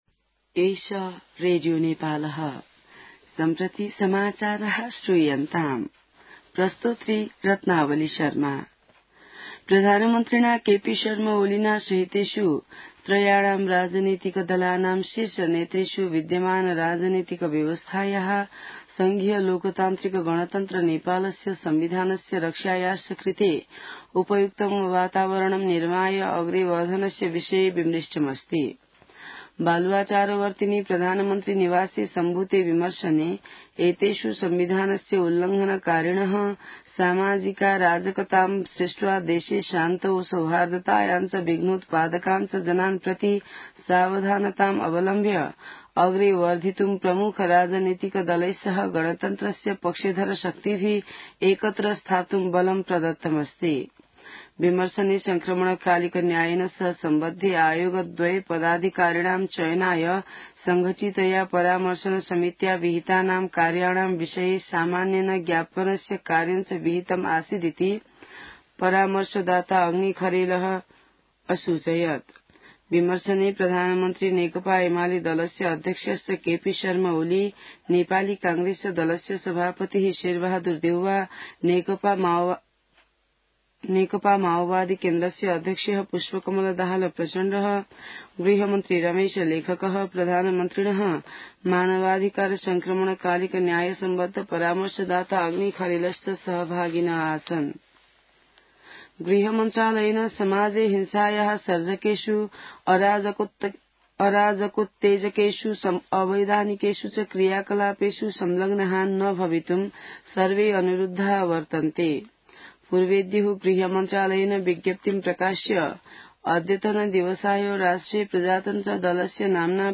संस्कृत समाचार : ७ वैशाख , २०८२